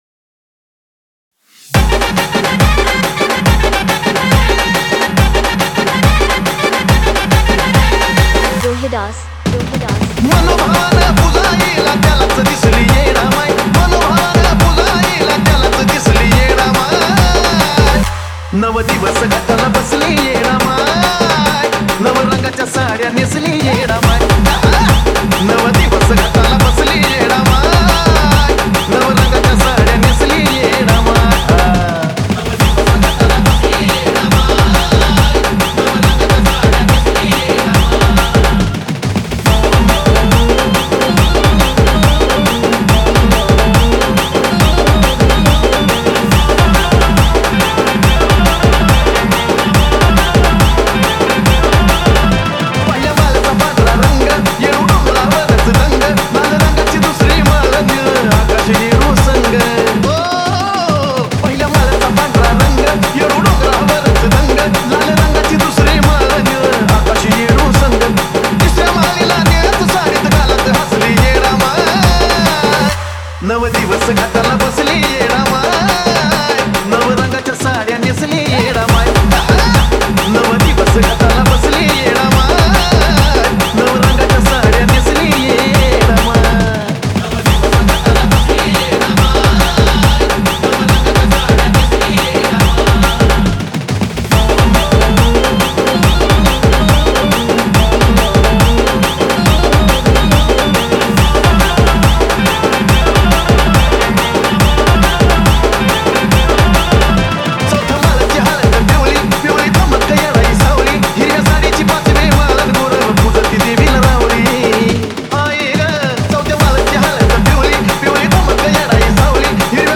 • Category: MARATHI SINGLE